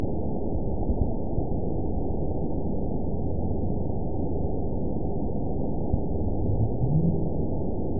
event 920684 date 04/03/24 time 05:28:11 GMT (1 year, 1 month ago) score 9.59 location TSS-AB03 detected by nrw target species NRW annotations +NRW Spectrogram: Frequency (kHz) vs. Time (s) audio not available .wav